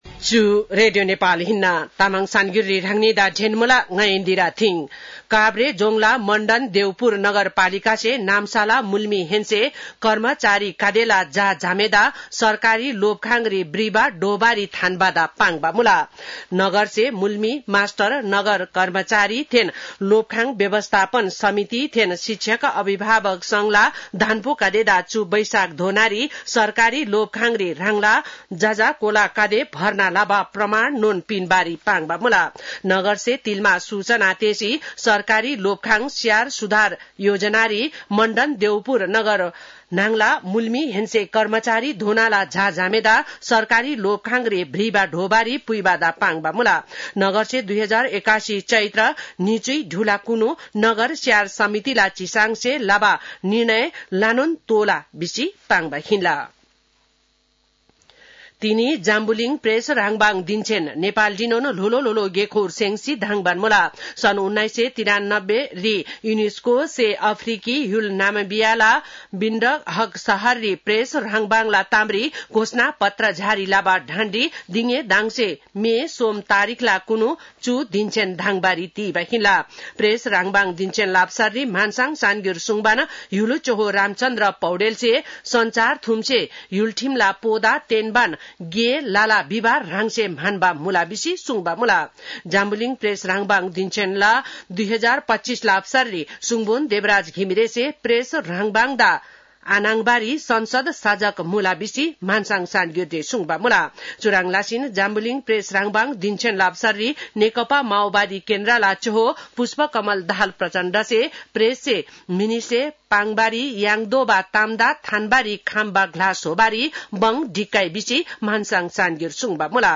तामाङ भाषाको समाचार : २० वैशाख , २०८२